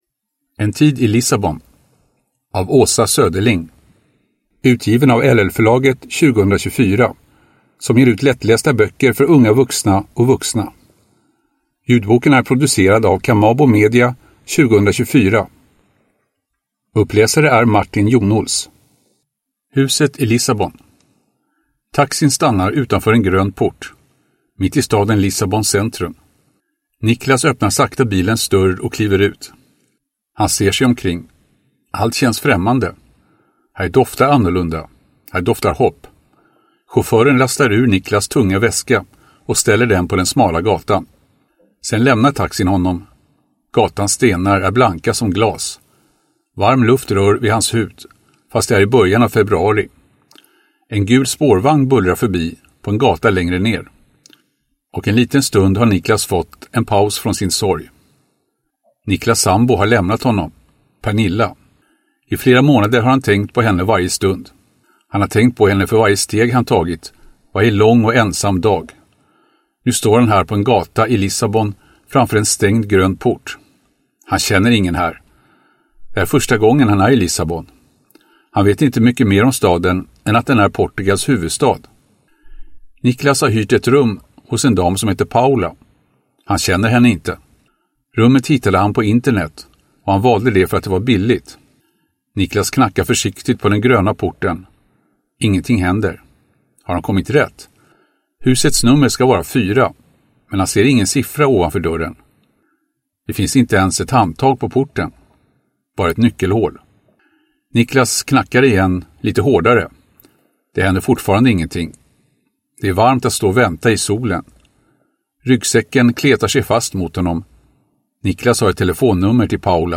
En tid i Lissabon (lättläst) (ljudbok) av Åsa Söderling